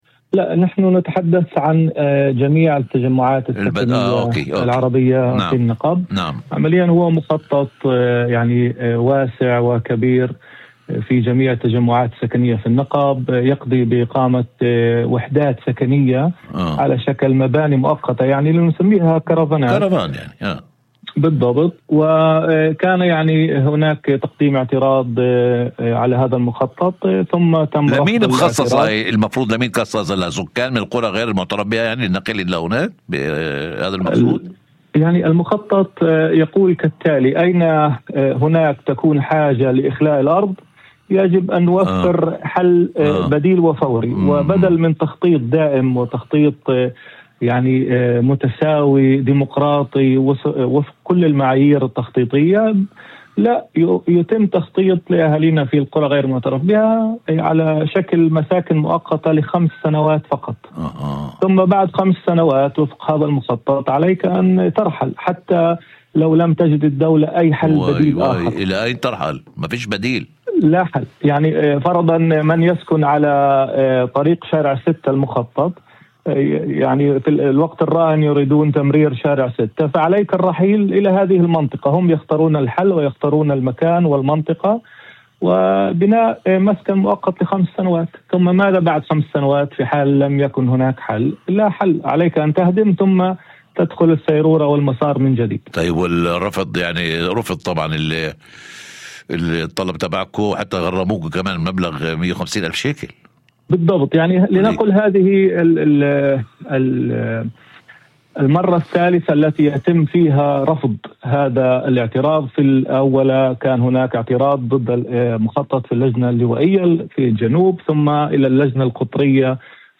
وأضاف في مداخلة هاتفية ضمن برنامج "أول خبر" على إذاعة الشمس، أن المخطط يمنح السكان مساكن مؤقتة لمدة خمس سنوات فقط، دون أي التزام بتوفير حل دائم بعد انتهاء هذه الفترة.